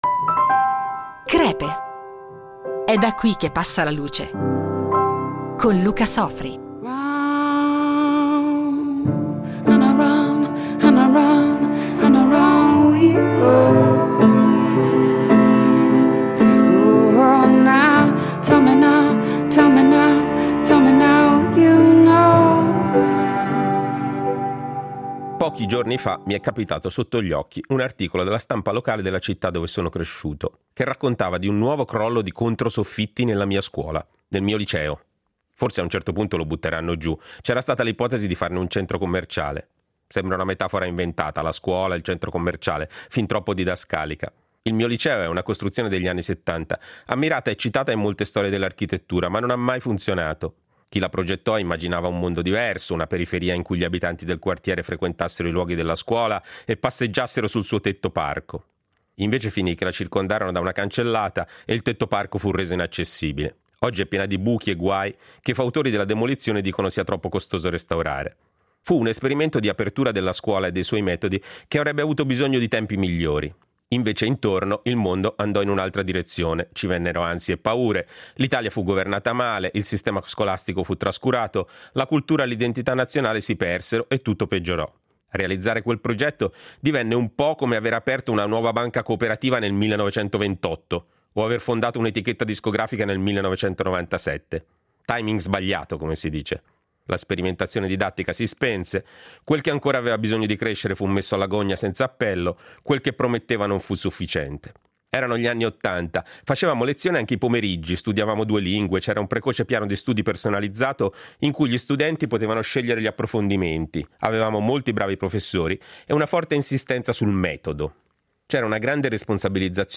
Il Direttore del giornale online “Il Post”, in questa serie in dieci puntate, ci racconta le sue illuminazioni: dai Peanuts a Tom Waits, da Keith Jarrett a House of cards.